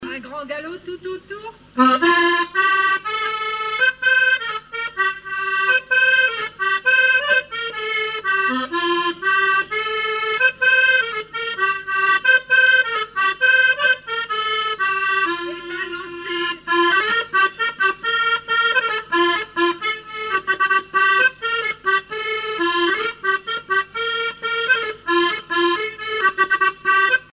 danse : quadrille : grand galop
Musique du quadrille local
Pièce musicale inédite